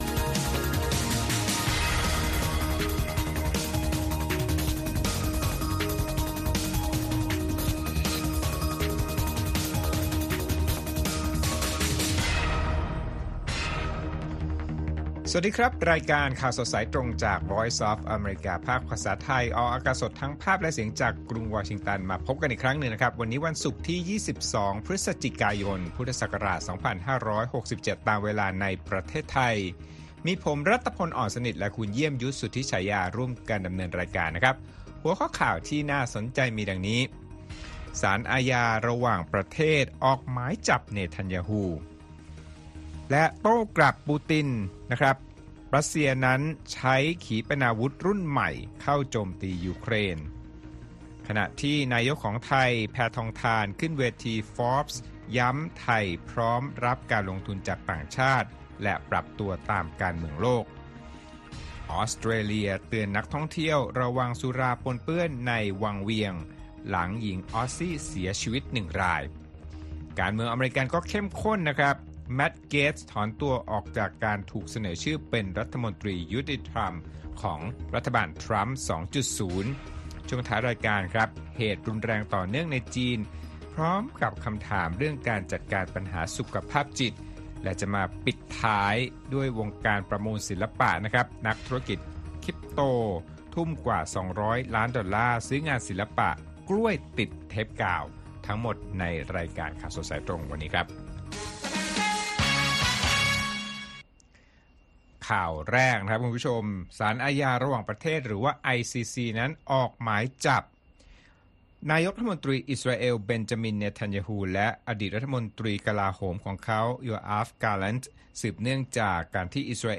1 ข่าวสดสายตรงจากวีโอเอ ภาคภาษาไทย พุธ ที่ 12 กุมภาพันธ์ 2568 - กุมภาพันธ์ 12, 2025 29:55